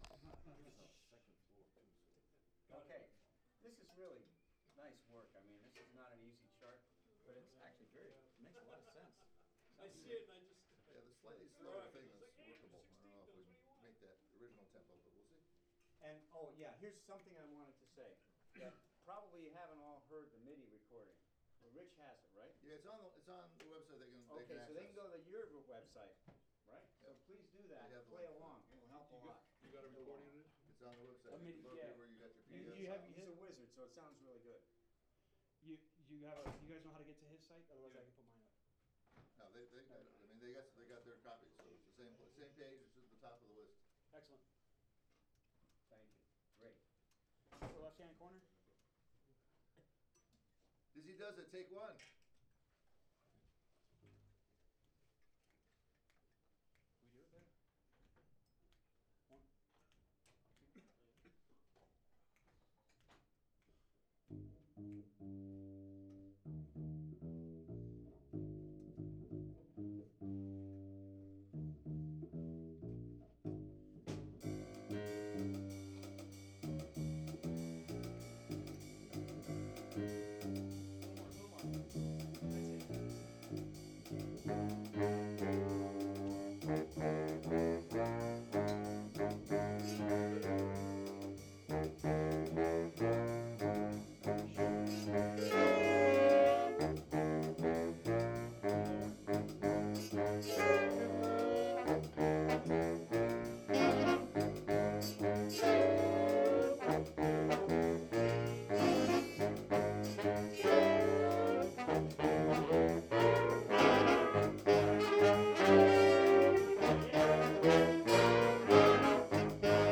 2012 Big band...
And below are the best recordings from the Monday 2/13 rehearsal.
[Large CD quality files - let em load.]